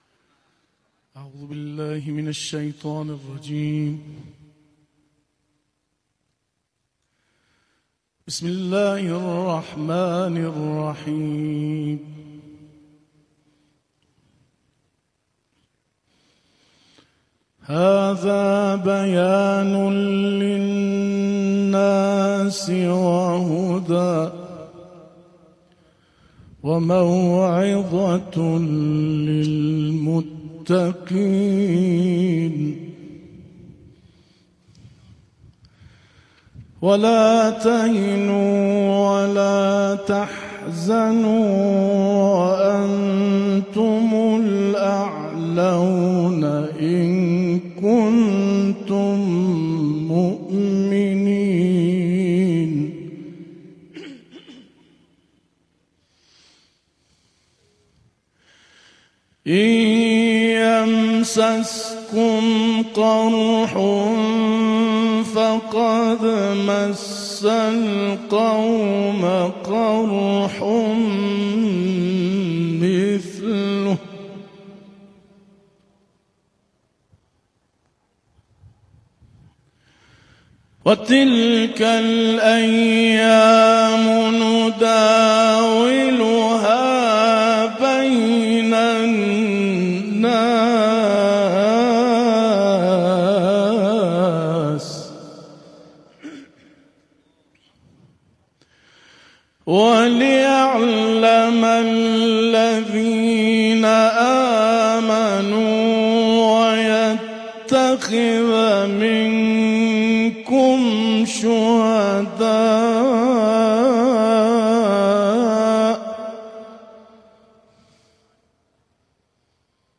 এই তিলাওয়াতটি "বিজয়ের পথে" (به سوی فتح) শিরোনামে আয়োজিত এক কুরআন মহফিলে তিনি পেশ করেছেন
এই মহফিলটি ৯ জুলাই ২০২৫ ইরানের কুরআনি সমাজের উপস্থিতিতে, শহীদ কমান্ডার আমির আলী হাজিযাদেহ (সাবেক আইআরজিসি স্পেস ফোর্স কমান্ডার)-এর কবরের পাশে আয়োজিত হয়েছে